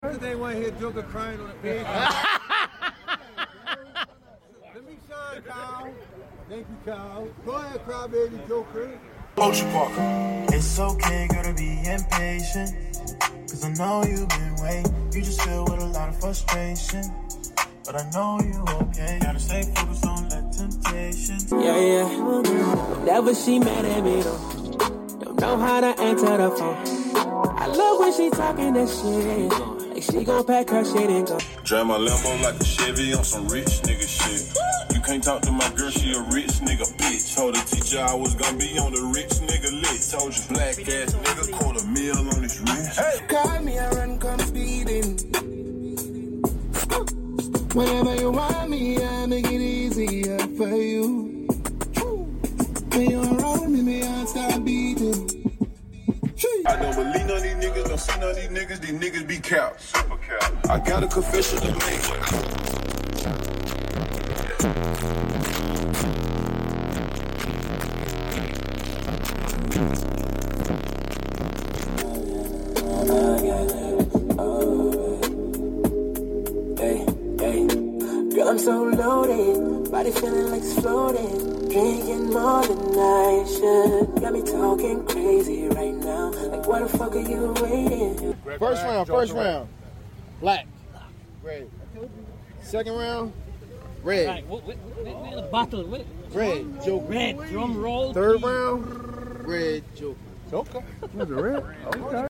Car Audio Middleweight Street Battles